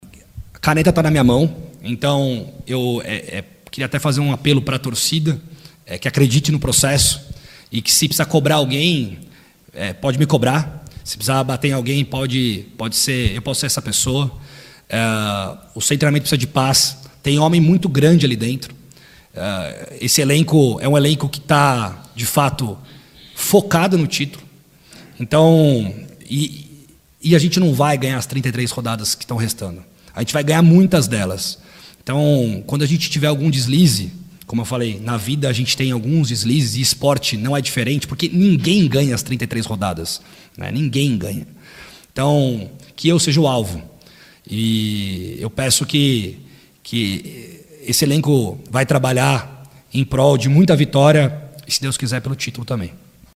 O dirigente concedeu uma entrevista coletiva nesta segunda-feira (29).